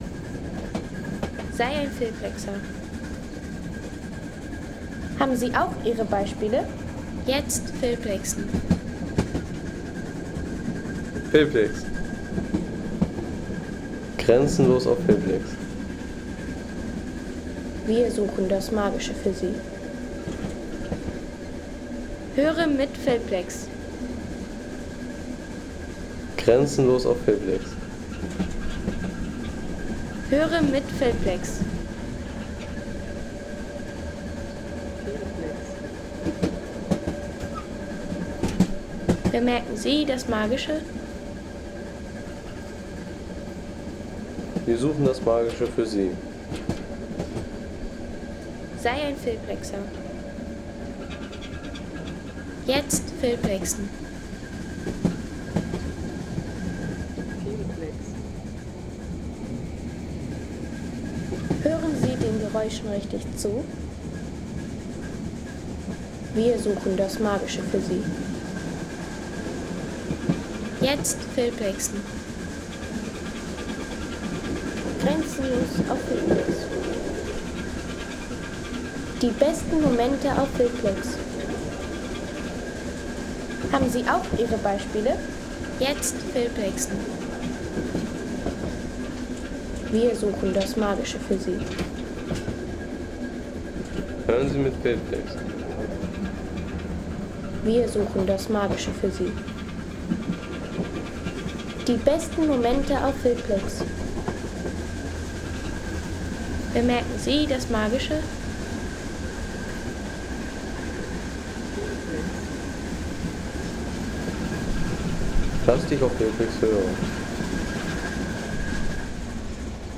Dampflokomotivenfart
Unvergessliche Dampflokfahrt mit einzigartiger Perspektive.